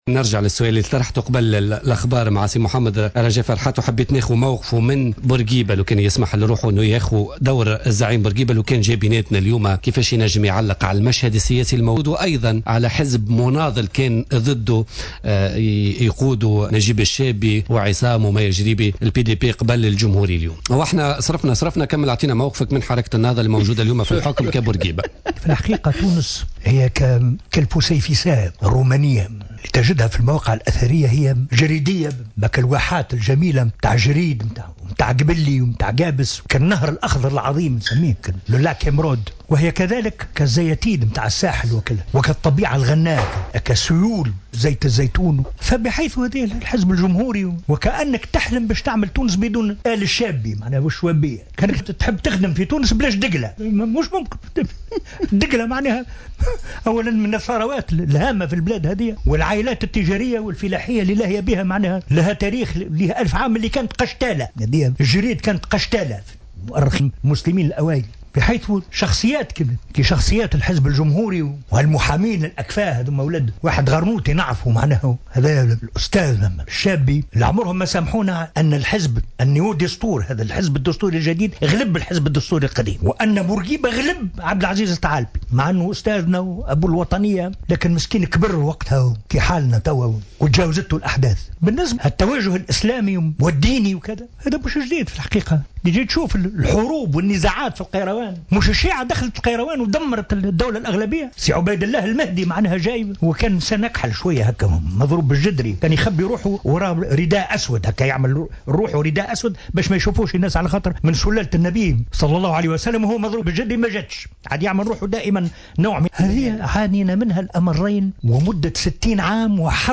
تقمص الفنان المسرحي رجاء فرحات ضيف بوليتيكا ليوم الجمعة 13 فيفري 2015 دور الزعيم الحبيب بورقيبة و هو يخاطب النائبة عن حزب حركة النهضة محرزية العبيدي الحاضرة في البرنامج.